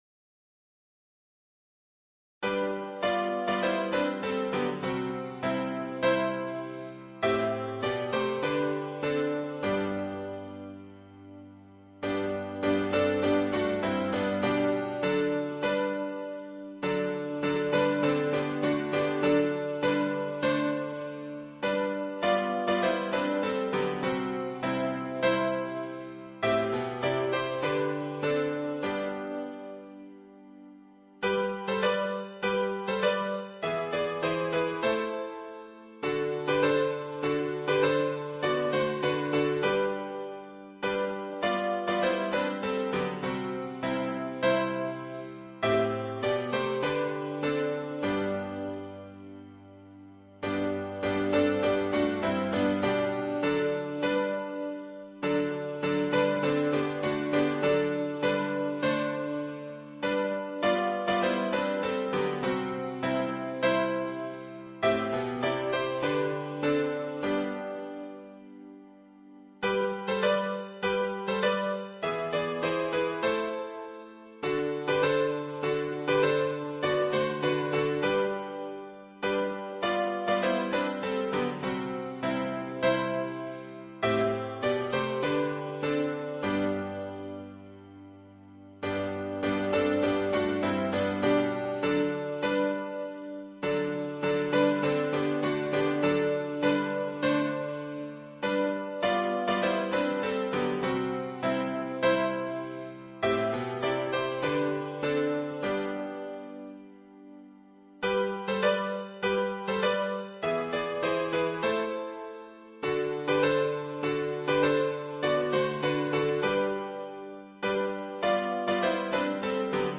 伴奏
原唱